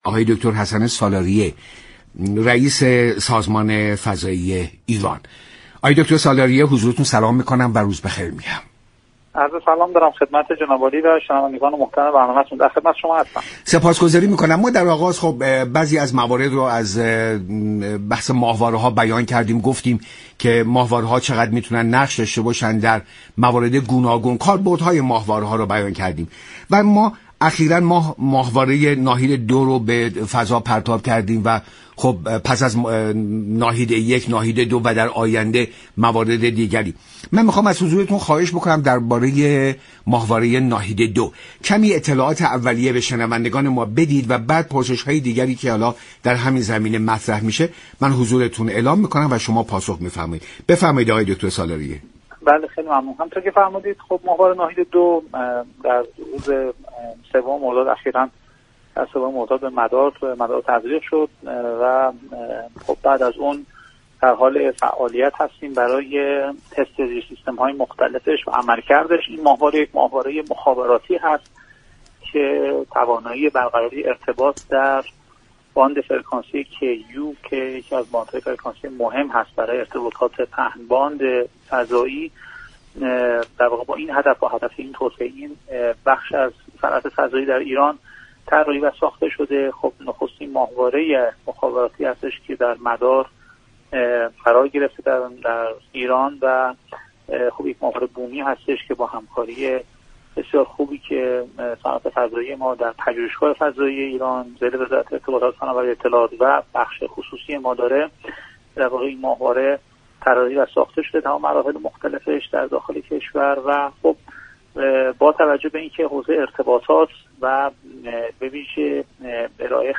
رییس سازمان فضایی كشور در برنامه ایران امروز گفت: تغییر و ساخت انواع ماهواره‌های مخابراتی در برنامه فضایی كشور قرار گرفته است.